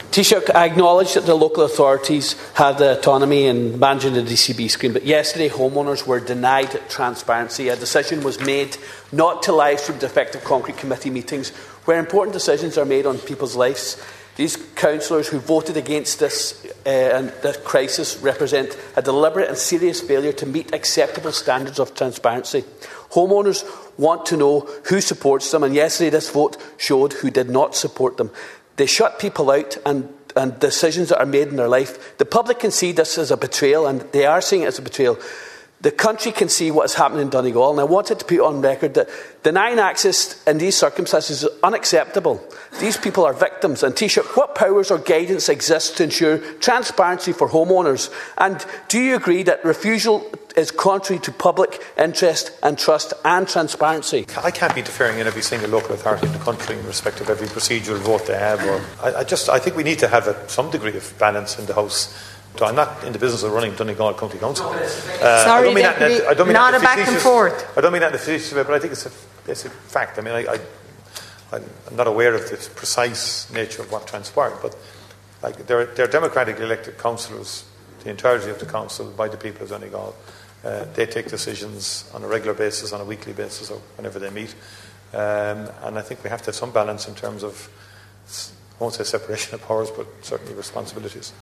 In the Dail this afternoon, Deputy Ward urged the Taoiseach to intervene……………